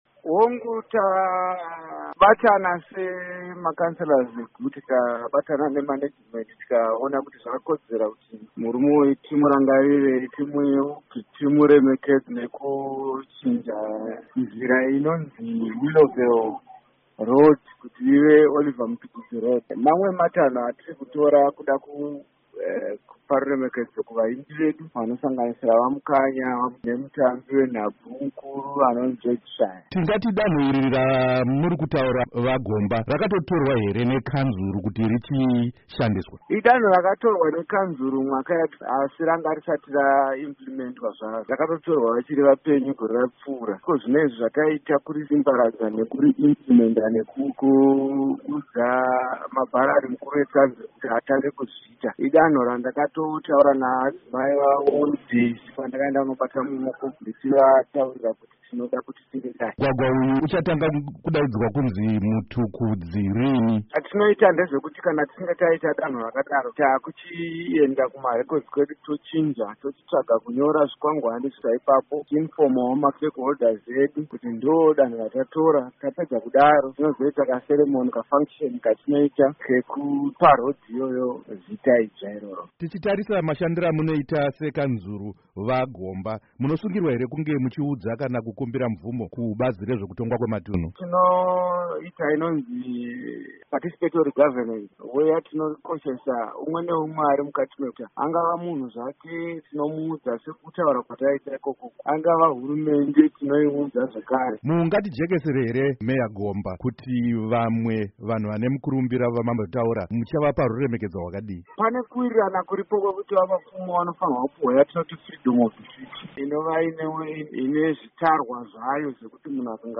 Hurukuro naVaHerbert Gomba